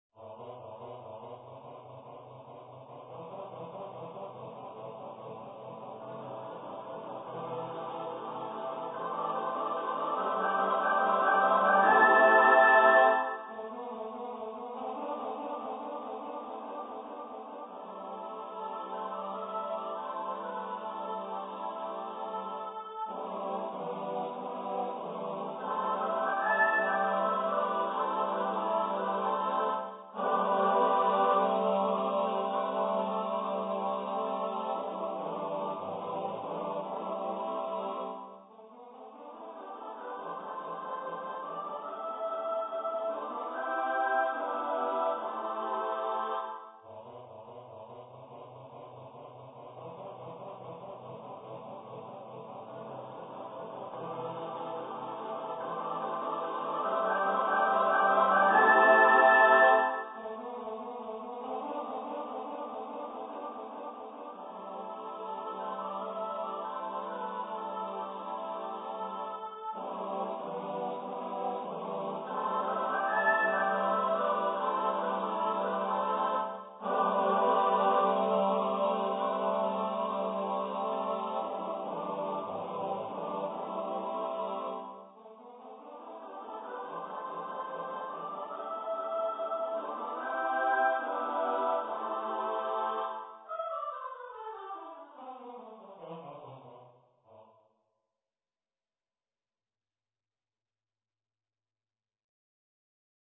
for unaccompanied mixed voice choir
Choir - Mixed voices (SATB)